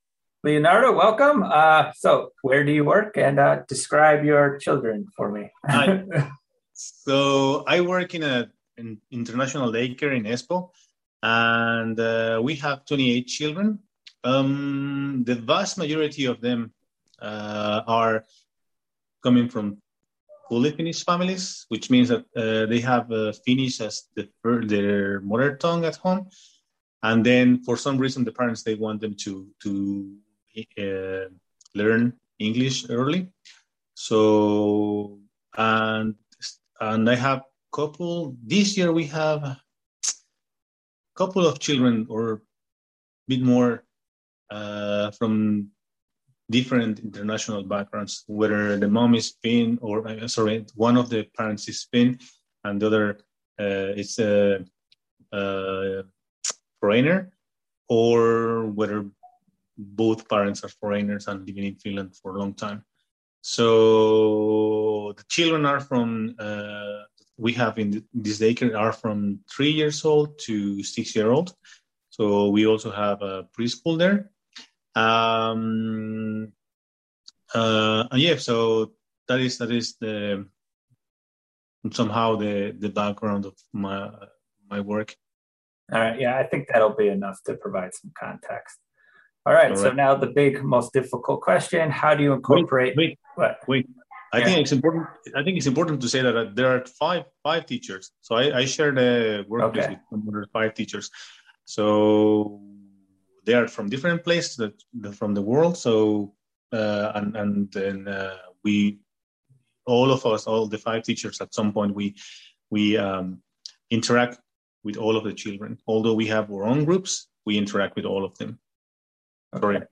early education interview